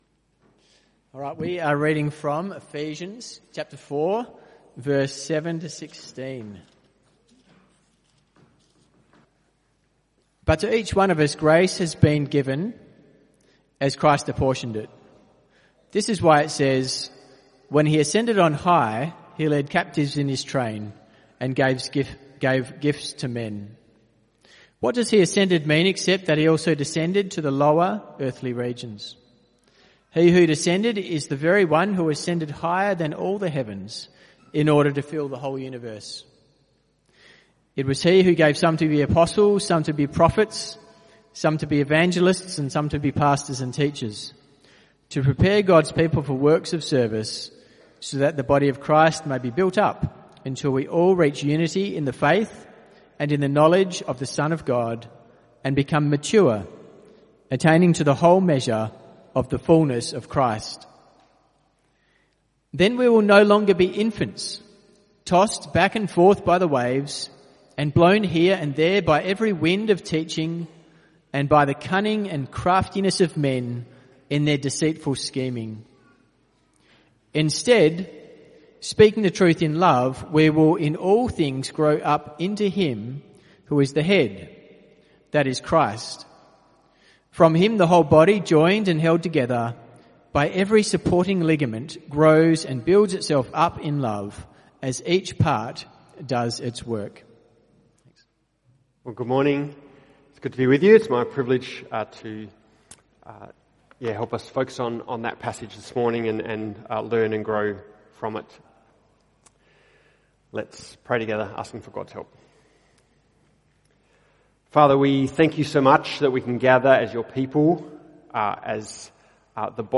CBC Service: 6 July 2025 Series
Type: Sermons